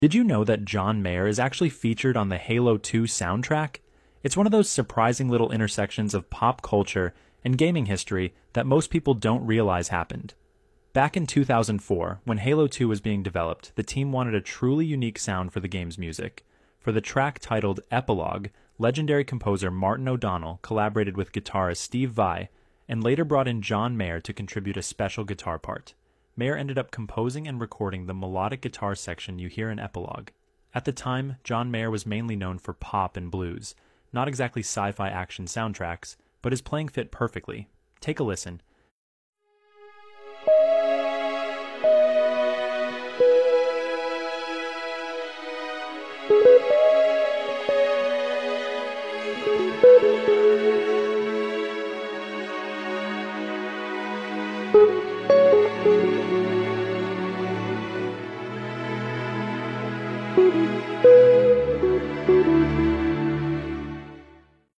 Guitar Solo